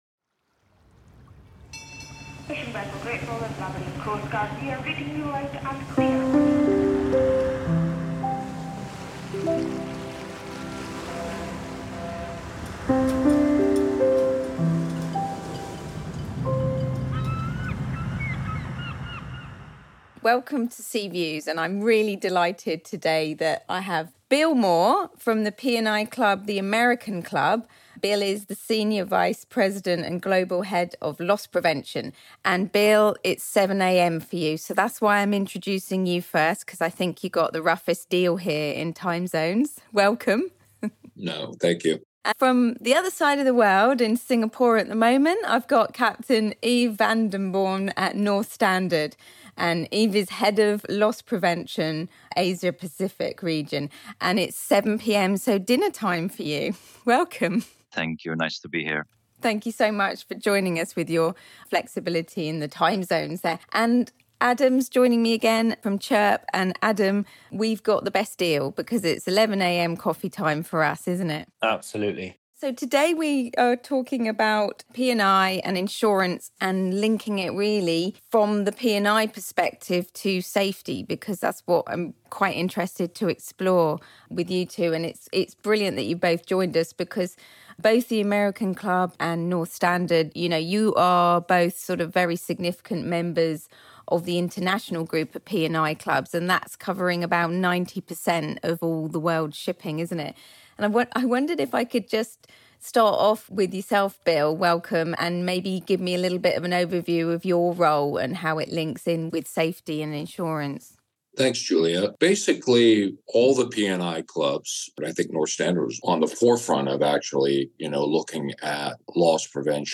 Together, they take listeners behind the scenes of how P&I clubs work to prevent losses, promote safety, and support the global maritime workforce. From mental health and manning issues to green fuels and shipping in war zones, this conversation explores the real-world impact of loss prevention strategies and the evolving risks in today’s maritime landscape.